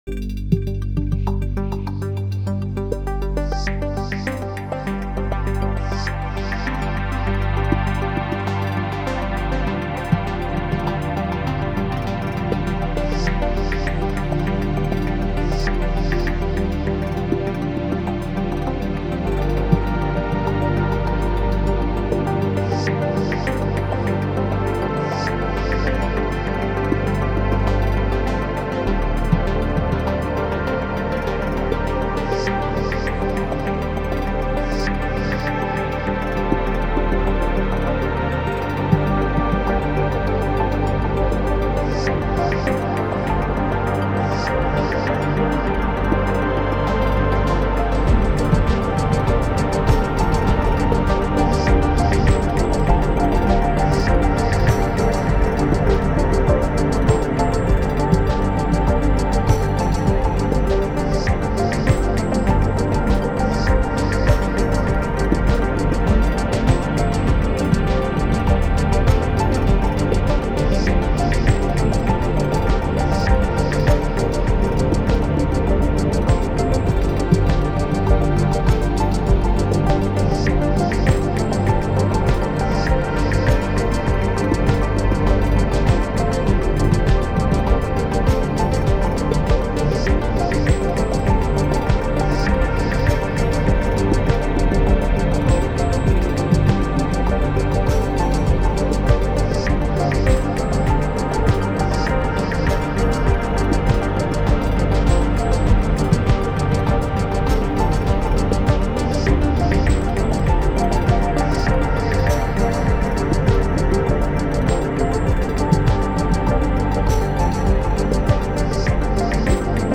Another mix